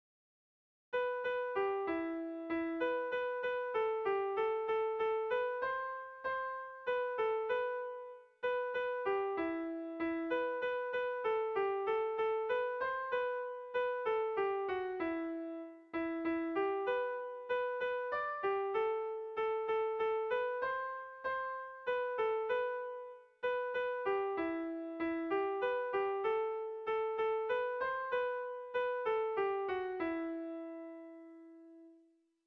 Kontakizunezkoa
Zortziko handia (hg) / Lau puntuko handia (ip)
A1A2A3A2